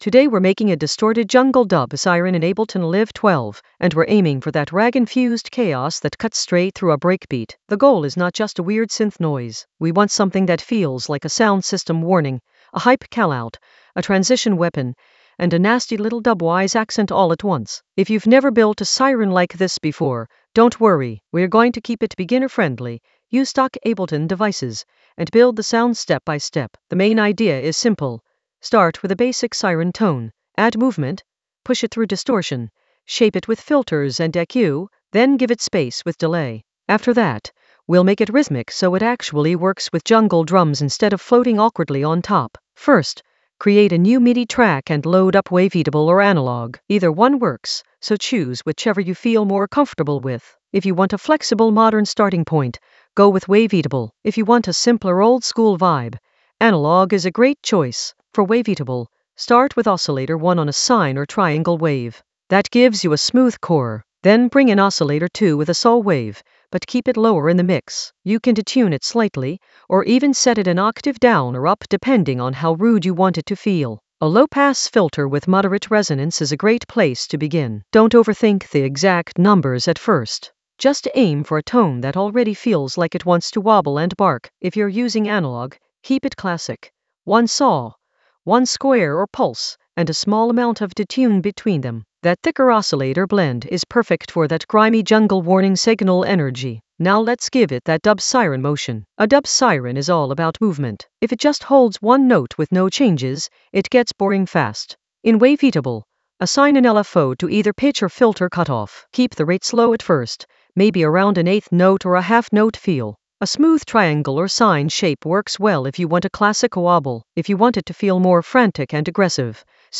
Narrated lesson audio
The voice track includes the tutorial plus extra teacher commentary.
An AI-generated beginner Ableton lesson focused on Distort jungle dub siren for ragga-infused chaos in Ableton Live 12 in the Basslines area of drum and bass production.